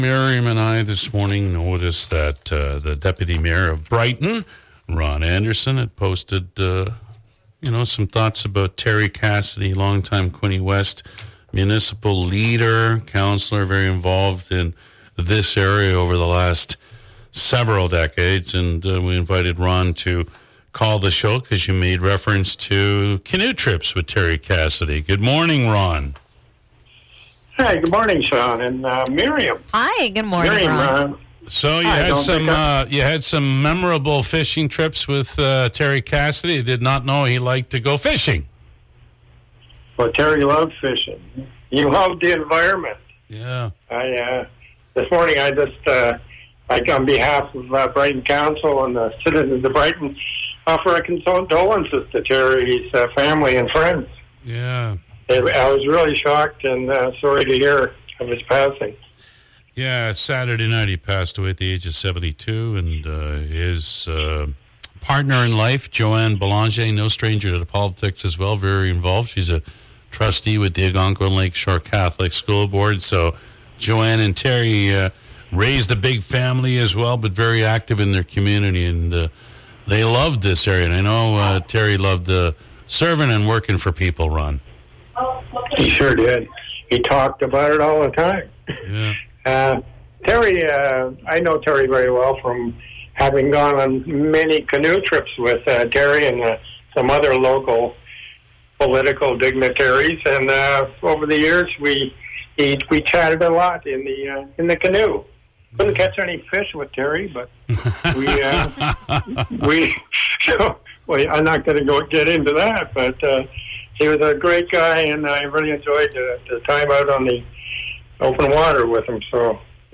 Brighton’s Deputy Mayor shares fond memories of the late Quinte West Councillor Terry Cassidy